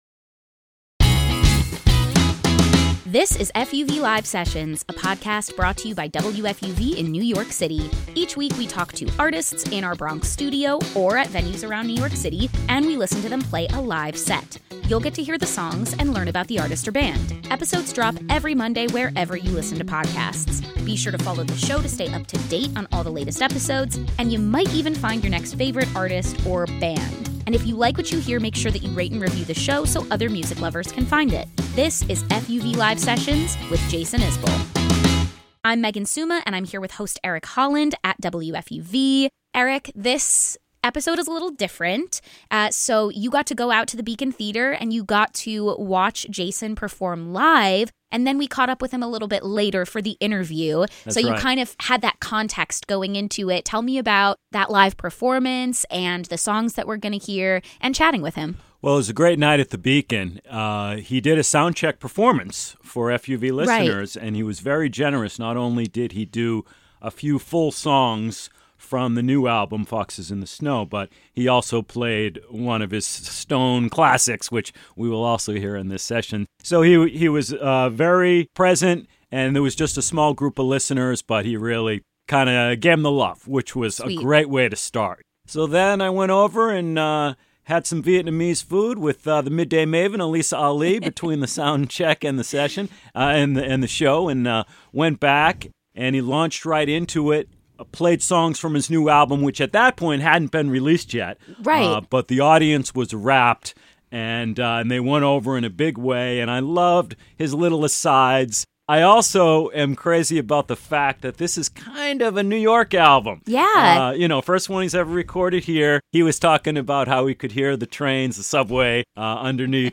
singer-songwriter
following his Beacon Theater performance in February